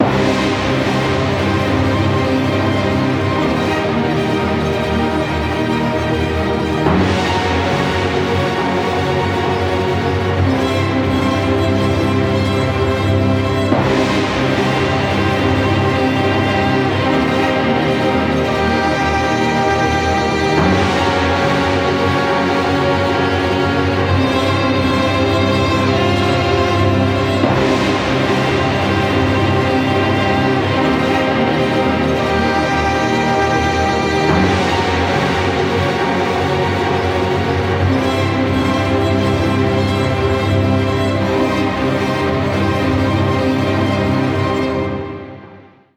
90's techno, a pinch of dub and funk and even some IDM